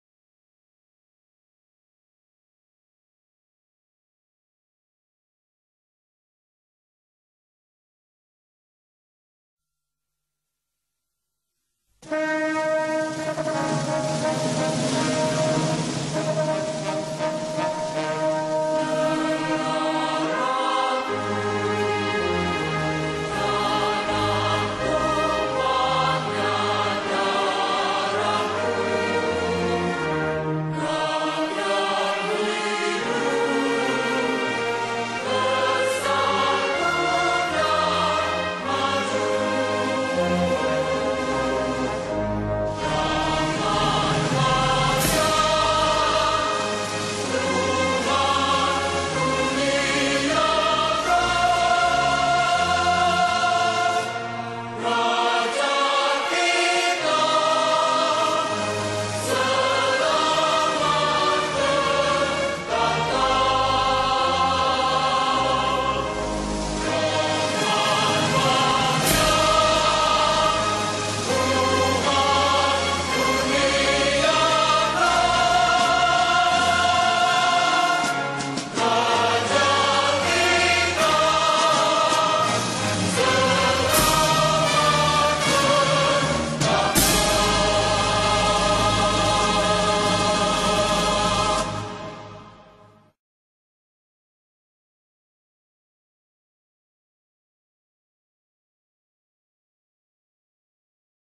с текстом